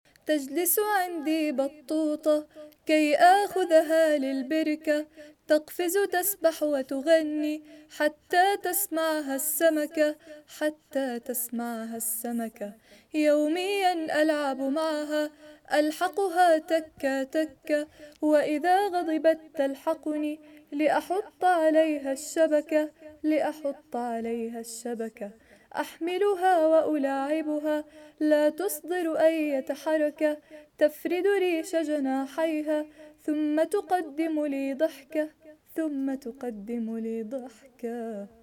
انشد لعبتي بطوطة عربي صف اول فصل اول منهاج اردني